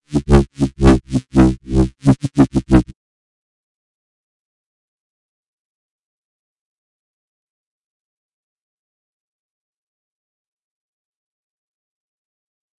摇摆不定的贝司声 " 贝司重采样3
描述：音乐制作的疯狂低音
Tag: 重采样 重低音 音效设计 摇晃